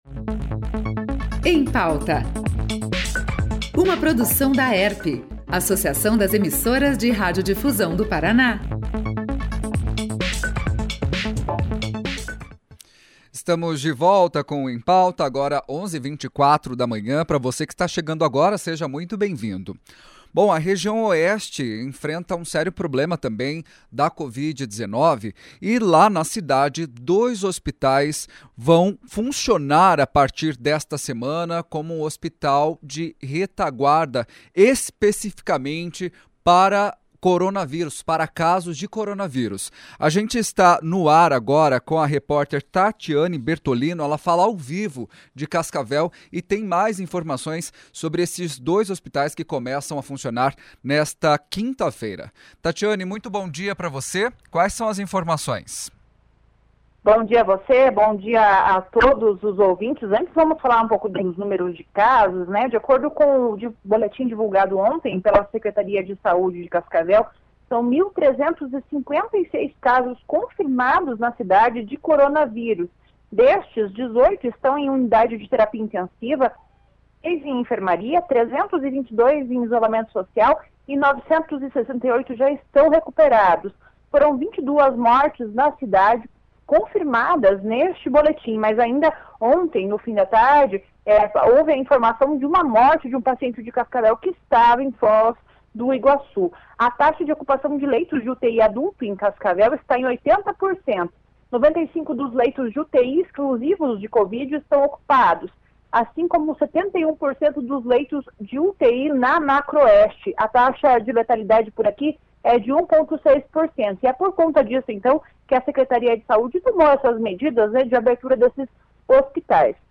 O Em Pauta desta quarta-feira fez um balanço da situação da Covid-19 no Paraná. Repórteres espalhados nas diferentes regiões do estado, trouxeram informações sobre as medidas que as prefeituras estão tomando neste momento em que os casos de coronavírus não param de crescer.